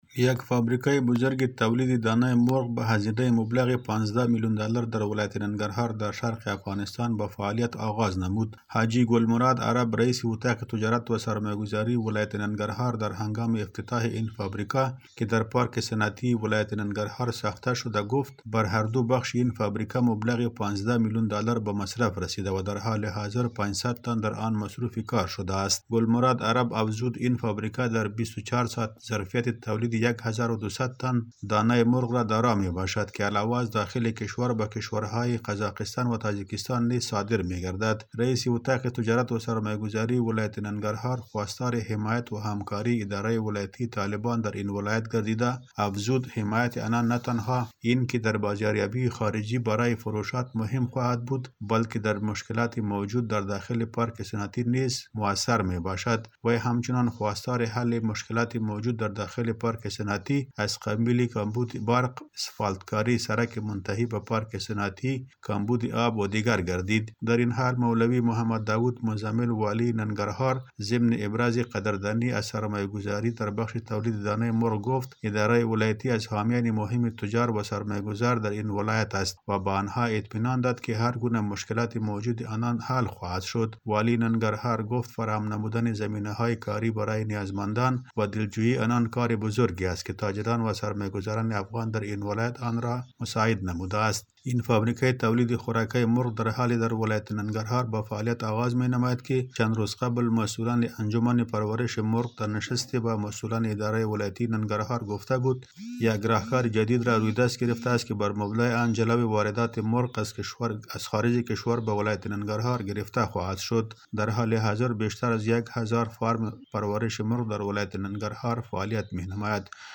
گزارش تکمیلی